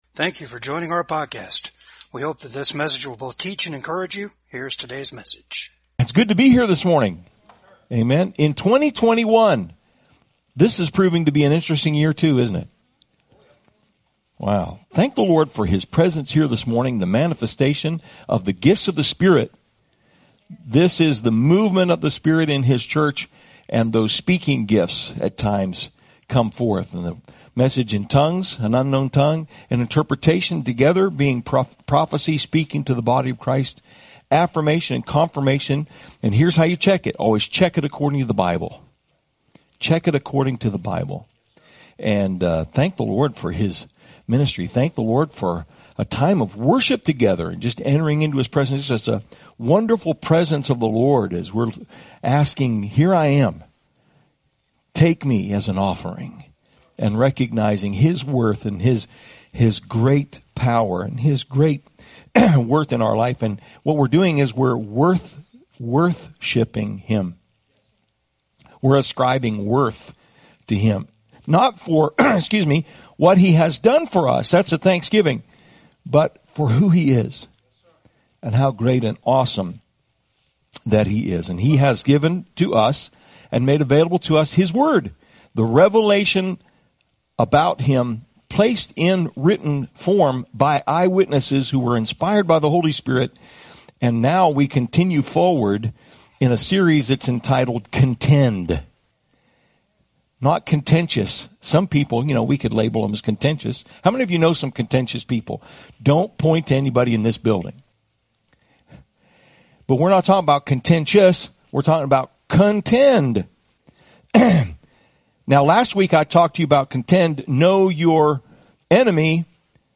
Jude 3 Service Type: VCAG SUNDAY SERVICE WE CANNOT AFFORD TO MERELY CONFRONT OURSELVES AS OUR GREATEST VISIBLE ENEMY.